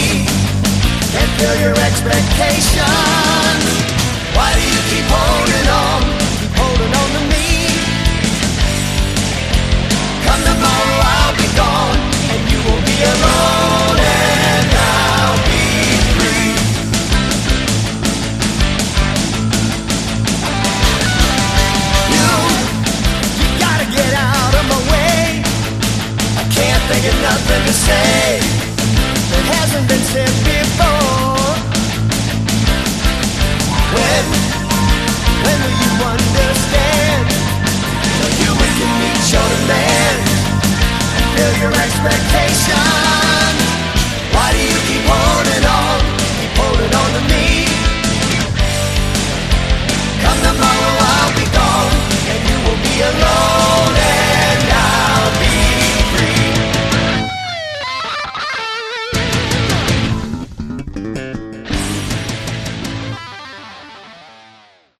Category: AOR/melod hard rock
Vocals, Guitars
Vocals, Keyboards
Bass
Drums